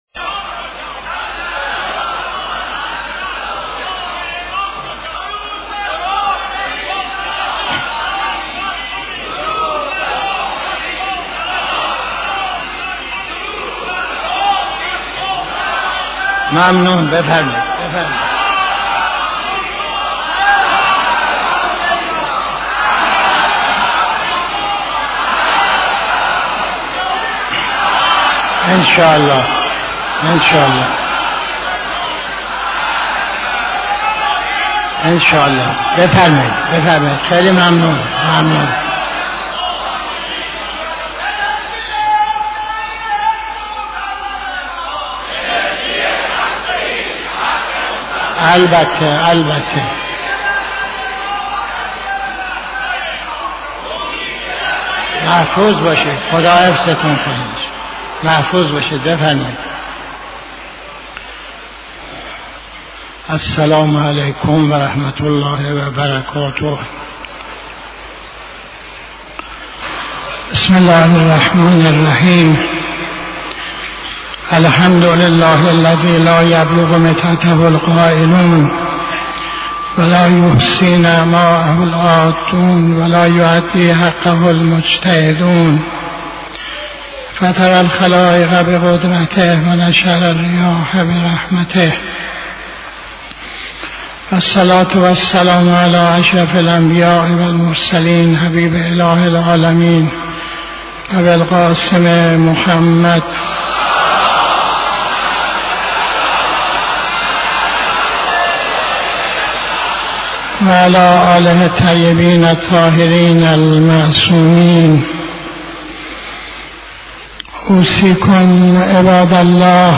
خطبه اول نماز جمعه 01-02-85